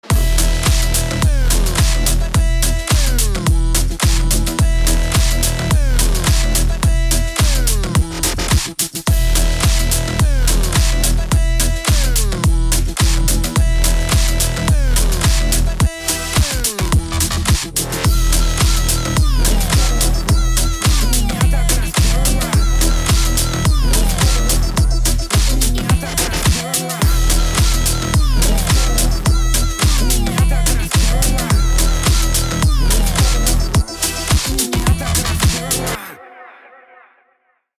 • Качество: 320, Stereo
dance
Electronic
club
забавный голос
смешной голос